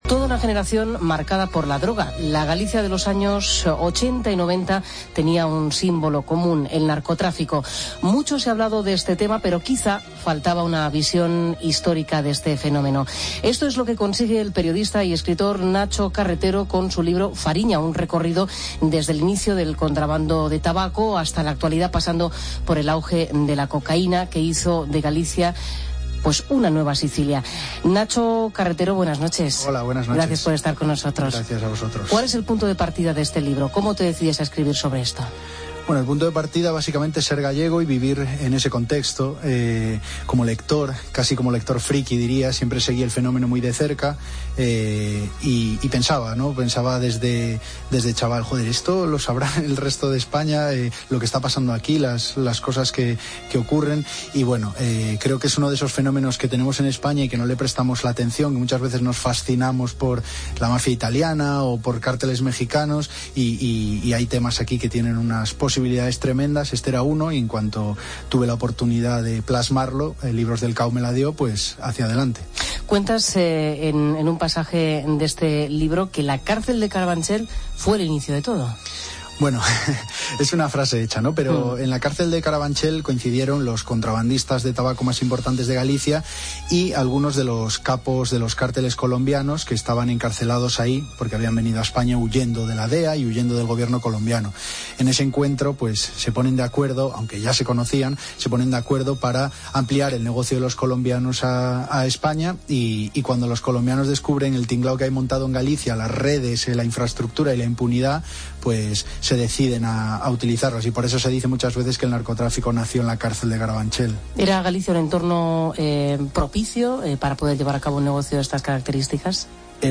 El periodista y escritor, autor de "Fariña", nos desvela la realidad del narcotráfico gallego recogido en su obra, una realidad aún vigente en la actualidad.